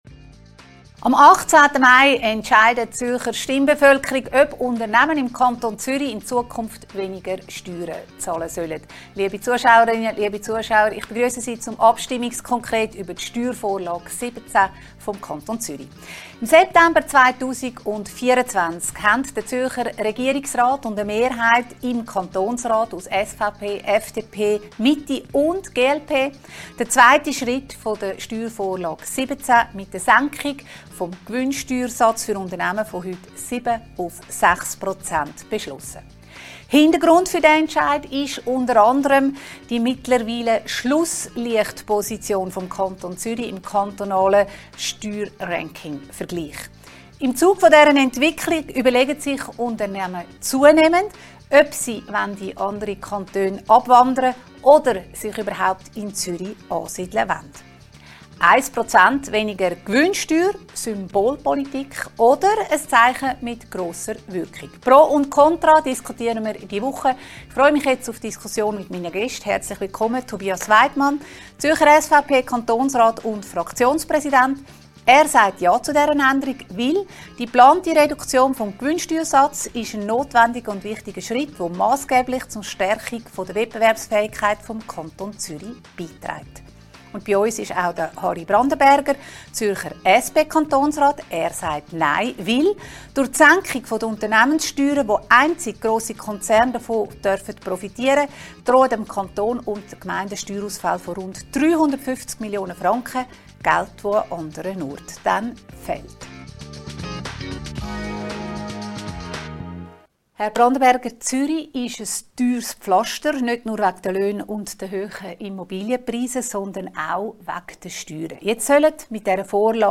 Abstimmungskonkret zur Steuervorlage 17 zur Senkung der Unternehmenssteuern im Kanton Zürich vom 18. Mai mit den beiden Zürcher Kantonsräten Tobias Weidmann, SVP und Harry Brandenberger, SP.